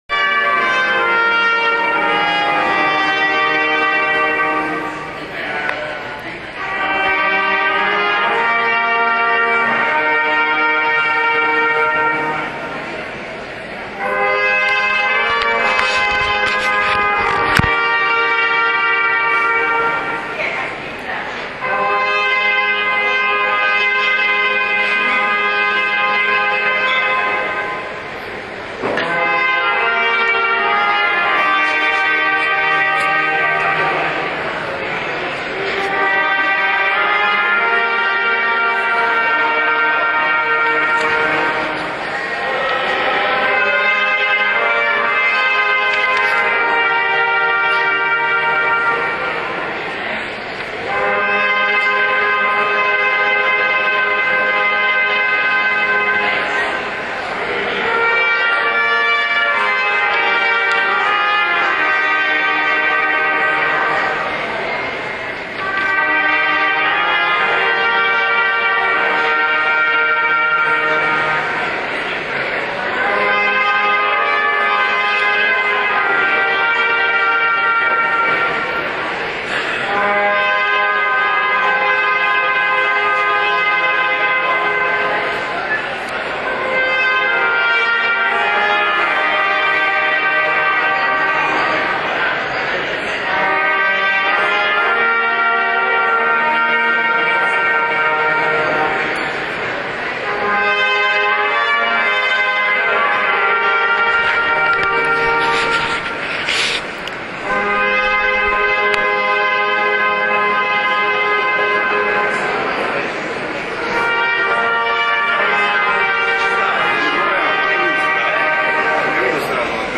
La banda di Vallepietra
la-banda-di-Vallepietra.WMA